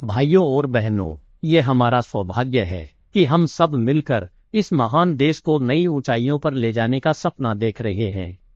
0e7927c indri-0.1-350m-tts